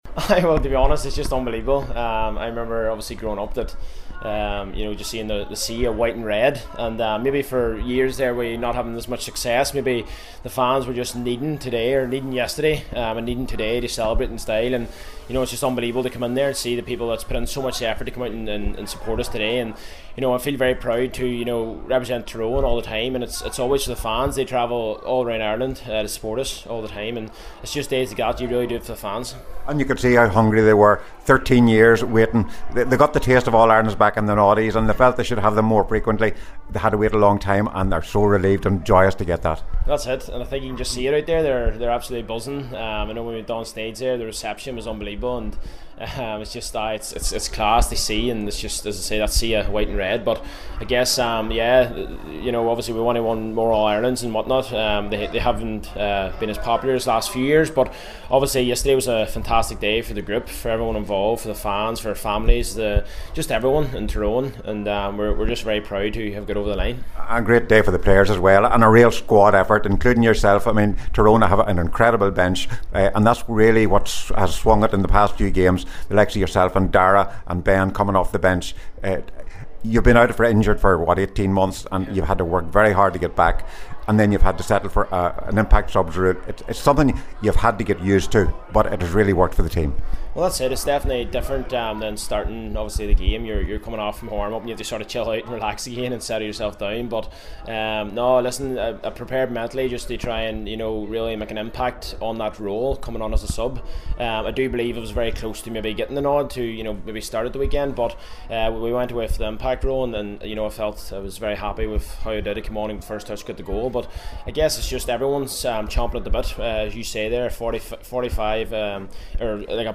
Goalscorer Cathal McShane spoke with the media at the homecoming…